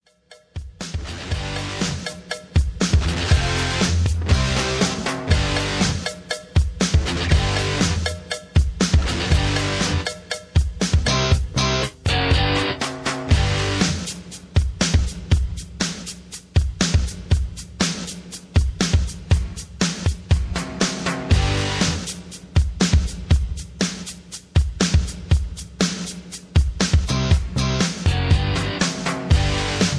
Tags: hip hop , r and b , rap , backing tracks